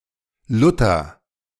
Lutter (German pronunciation: [ˈlʊtɐ]